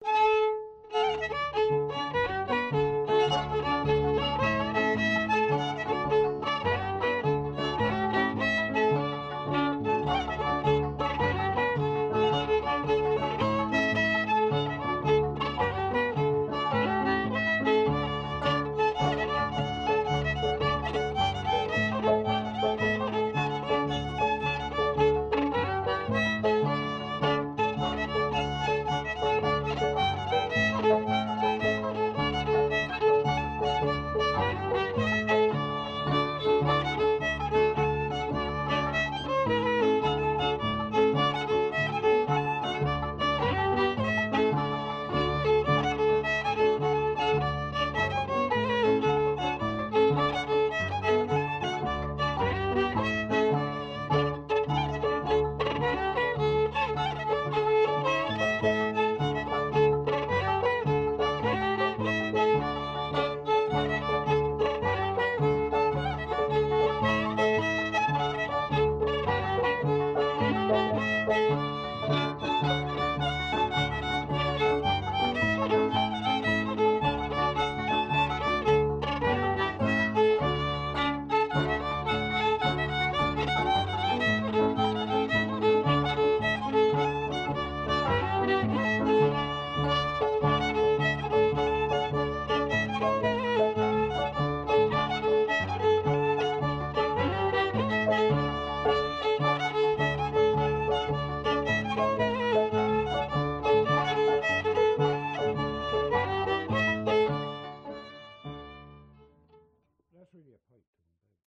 pno